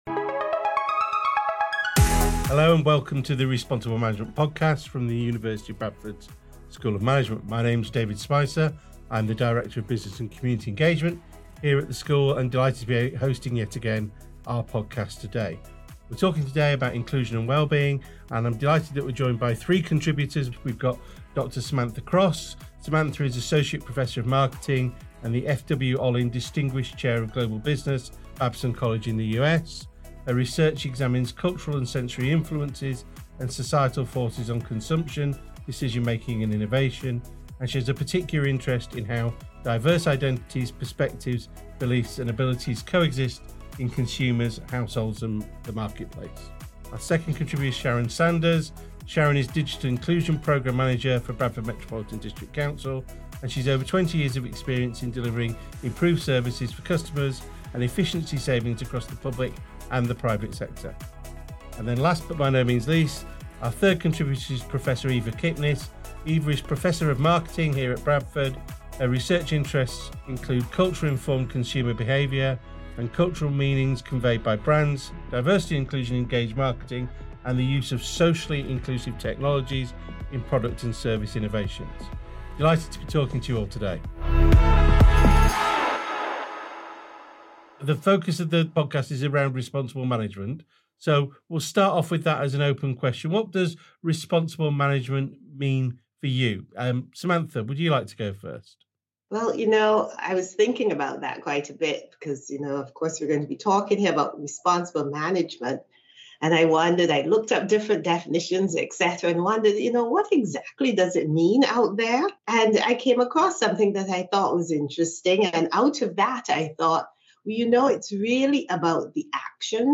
host 3 guest speakers as we're joined by regional and global guests.